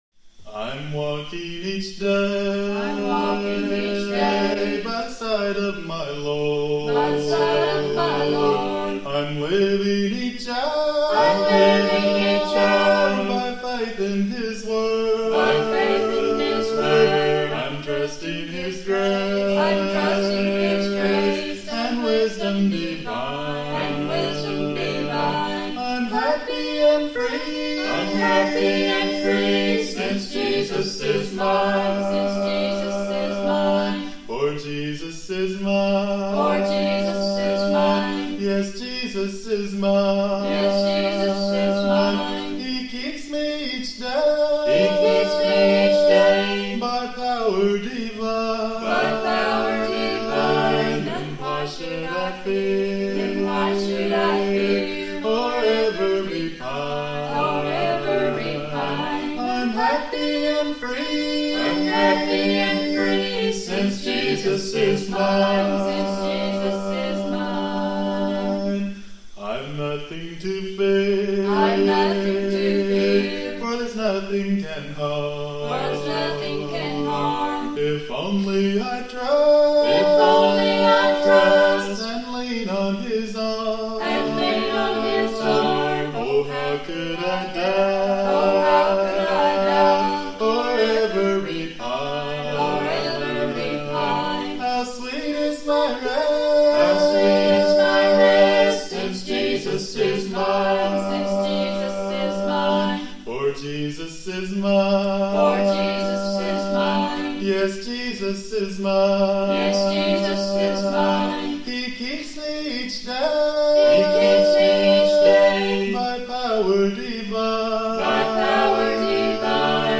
Key: A♭